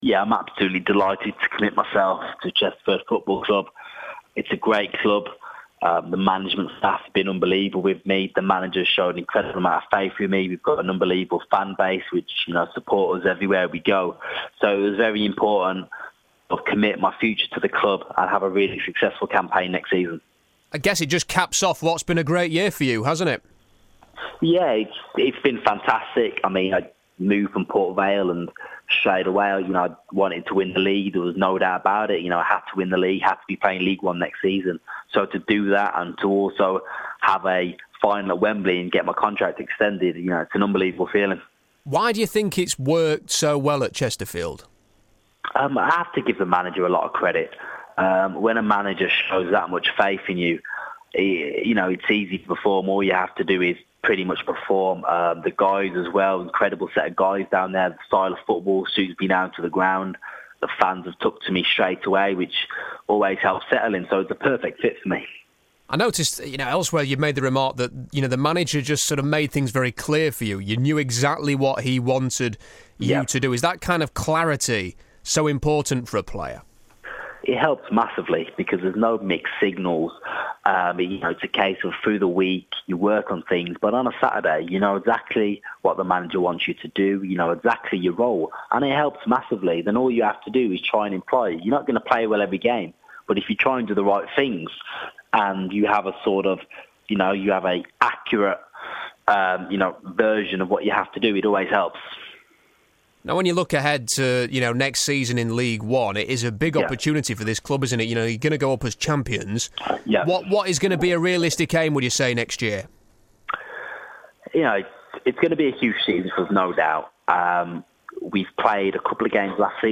INTERVIEW: Chesterfield midfielder Sam Morsy on his new deal at the ProAct Stadium